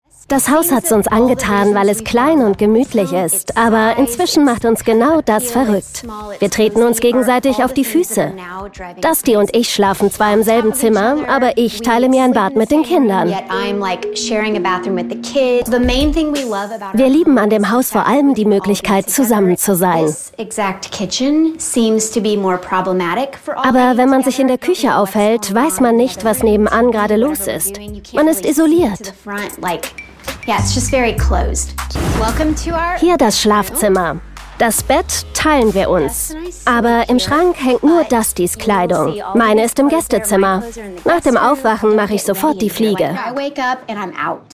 sehr variabel, markant, hell, fein, zart, plakativ
Jung (18-30)
Lip-Sync (Synchron)